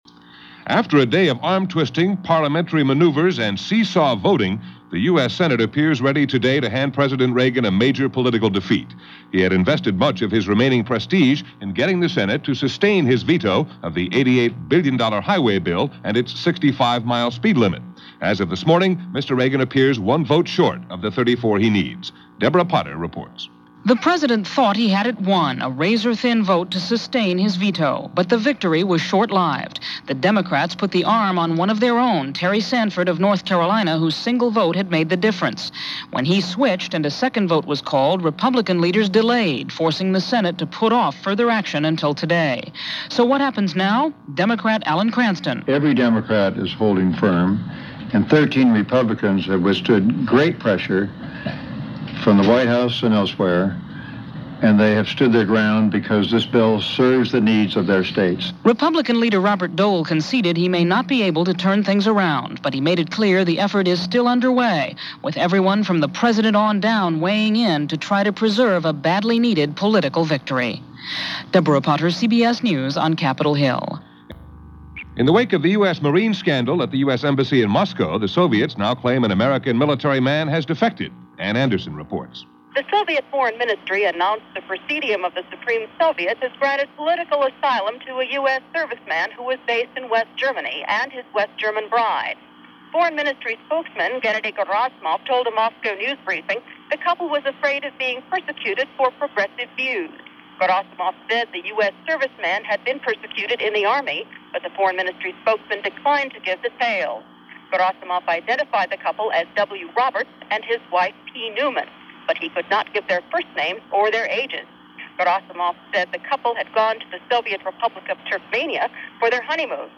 And that’s just a sample of what went on this moderately out-of-control April 2nd in 1987, as reported over The CBS World News Roundup.